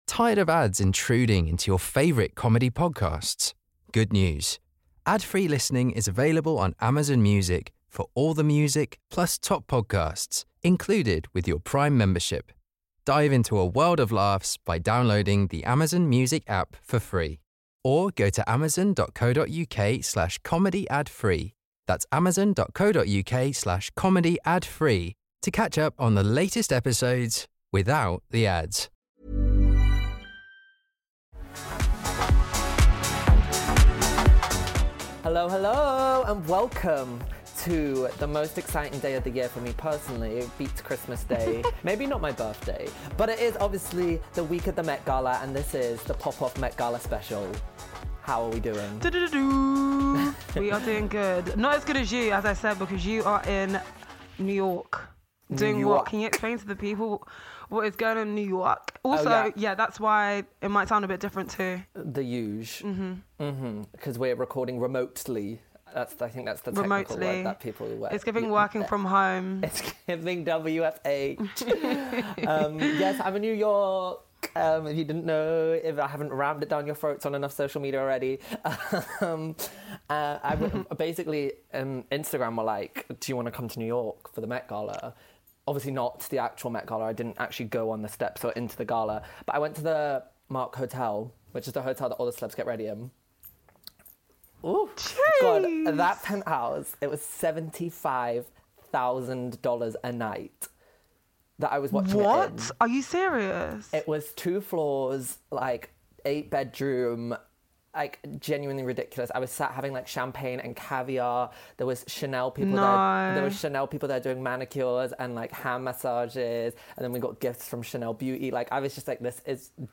Behind the scenes at The Met Gala (recorded in New York)!